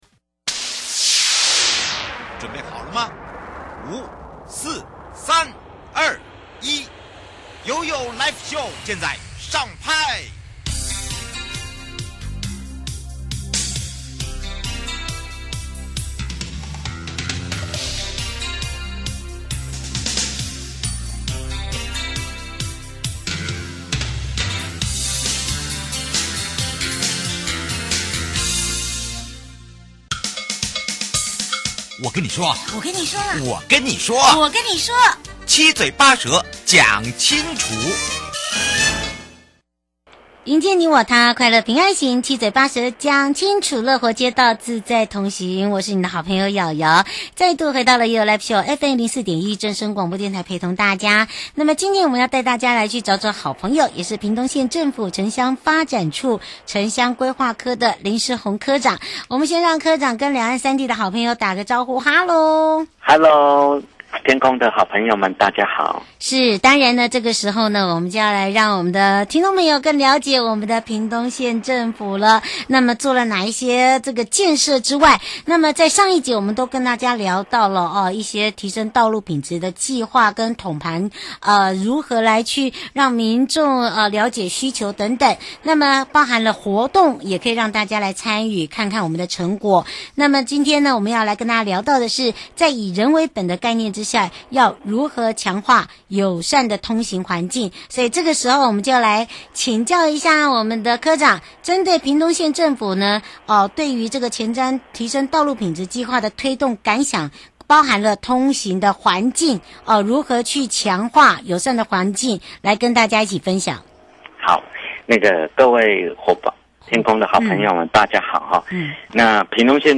受訪者： 營建你我他 快樂平安行-屏東縣政府在推動以人為本的前瞻基礎建設硬體部分最重要要把關的部分是什麼?有什